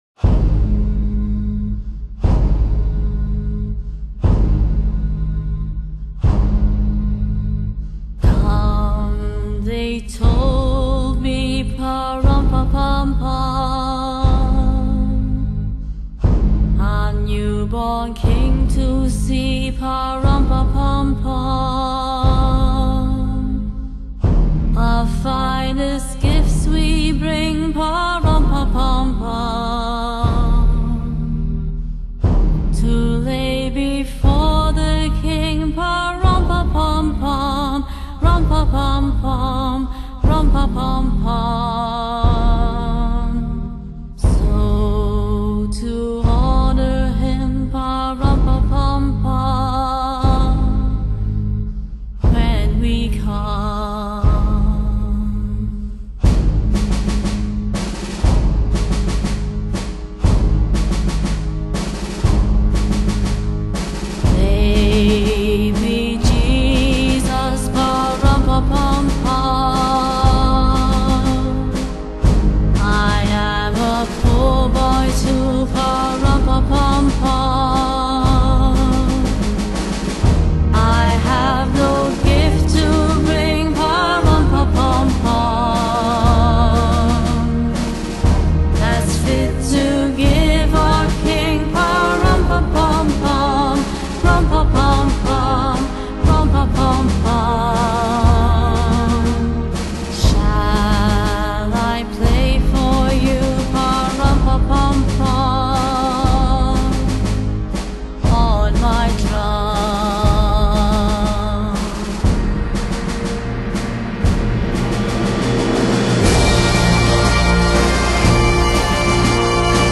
來自英倫/愛爾蘭的五人Doo-Wop復古男孩合唱團助陣